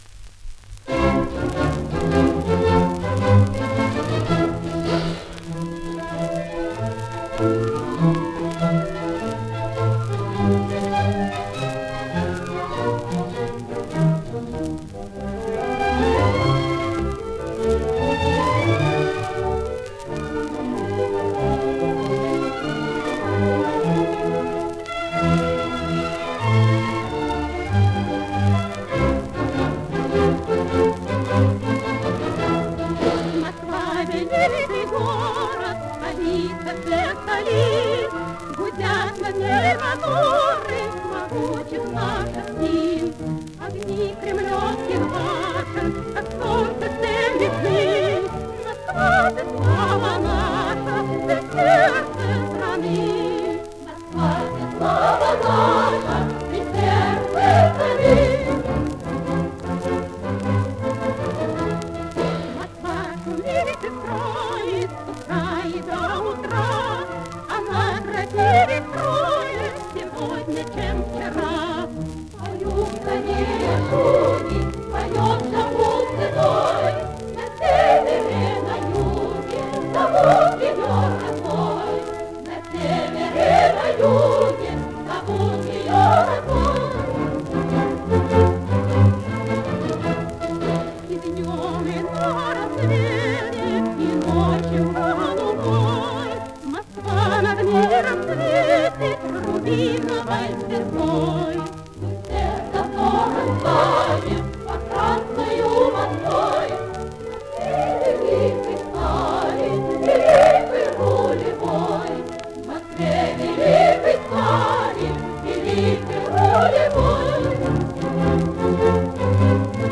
акк. женский хор п.